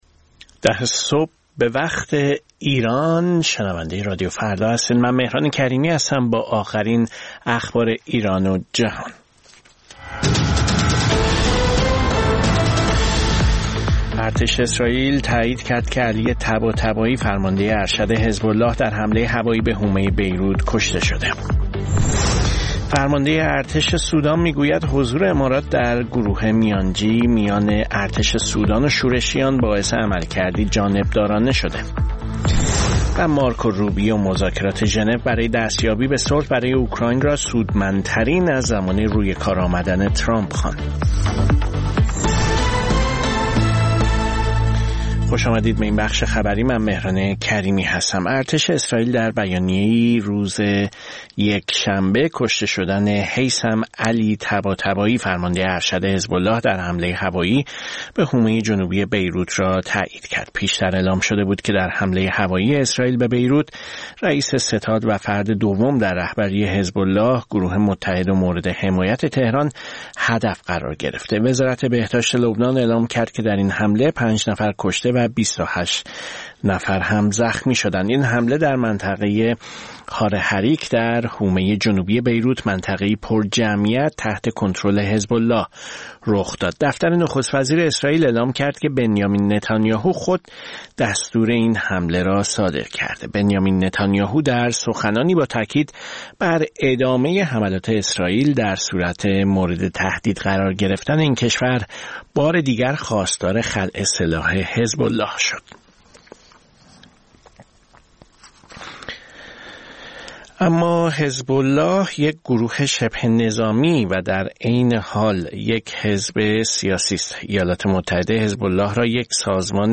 سرخط خبرها ۱۰:۰۰